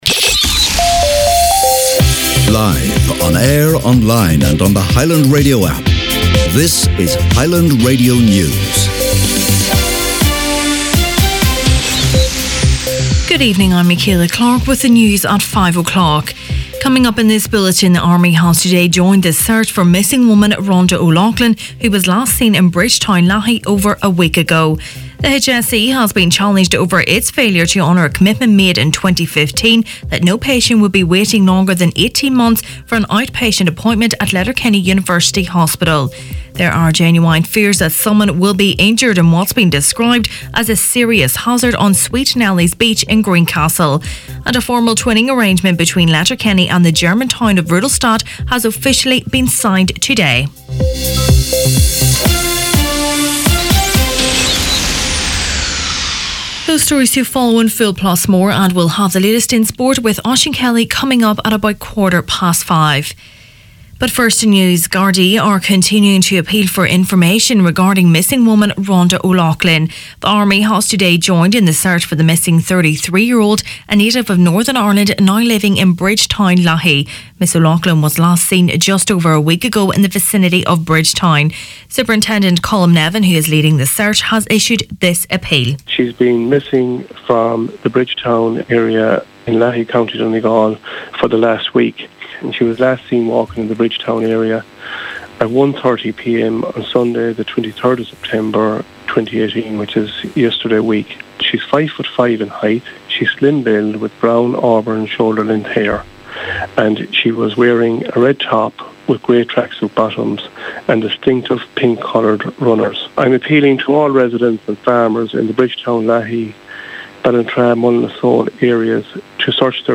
Main Evening News, Sport and Obituaries Monday October 1st